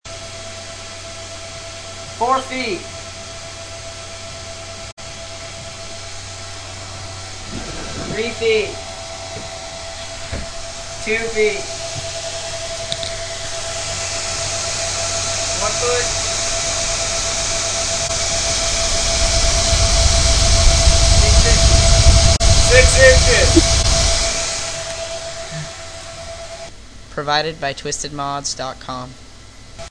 Vantec 80mm Tornado Case Fan
It was pretty darn loud for an 80mm fan. One of the loudest fans I have ever heard.
tornado1.mp3